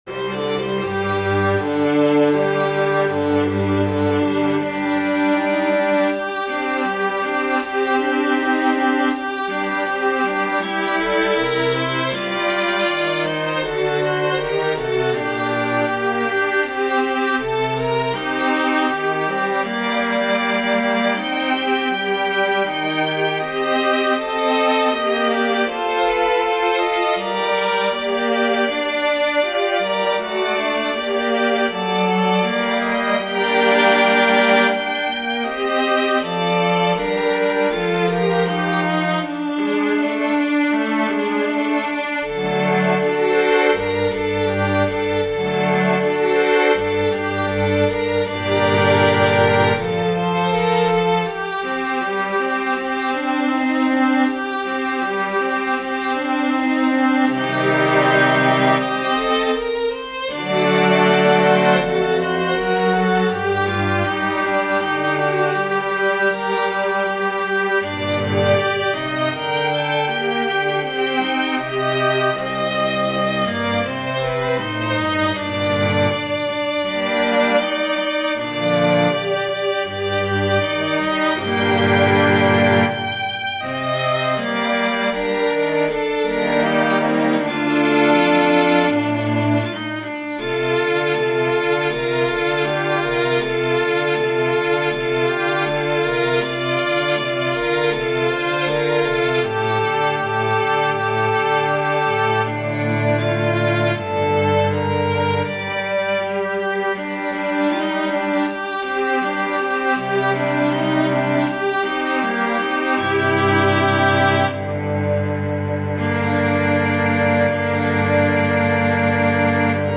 Krontjong muziek Ruwenberg vertelconcert - Dag van het Kasteel
omgezet voor strijktrio
Krontjong_Pandan.mp3